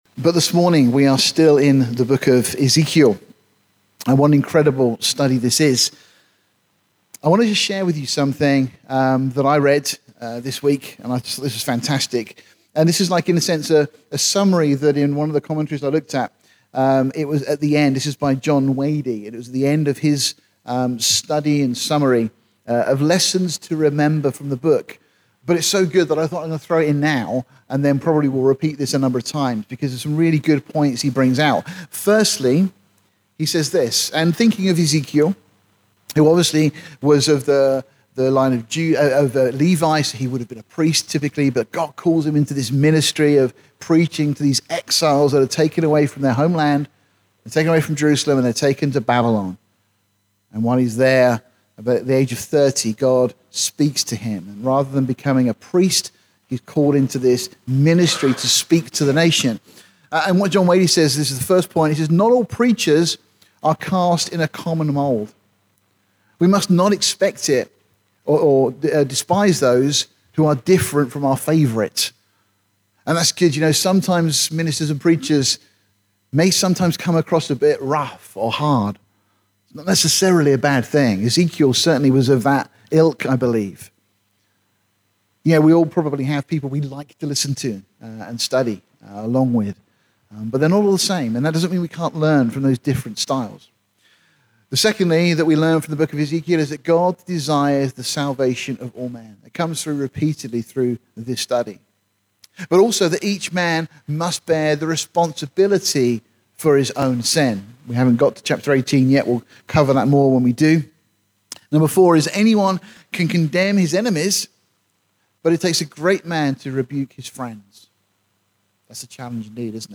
This sermon starts with a set of 23 “Lessons to Remember” as distilled from the book of Ezekiel.